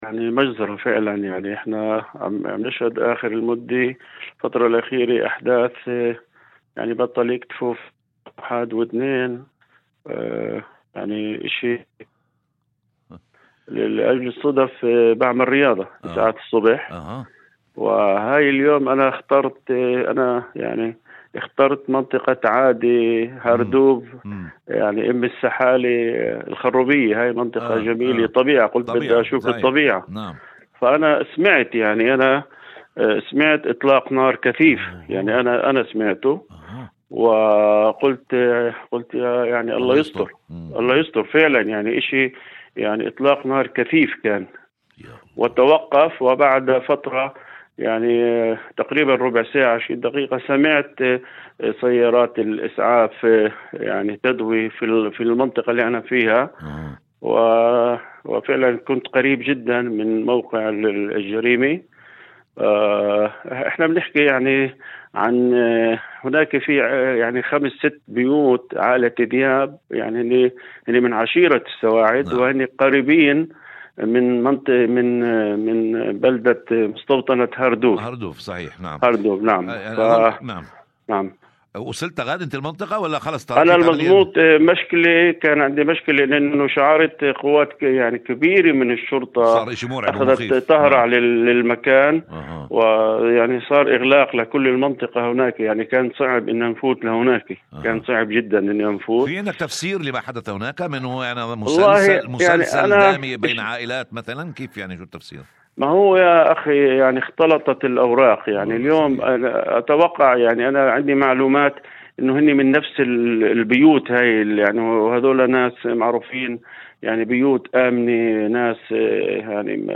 مداخلة هاتفية لإذاعة الشمس عبر برنامج "أول خبر"